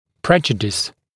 [‘preʤədɪs][‘прэджэдис]вред, ущерб; наносить ущерб, вред, причинять вред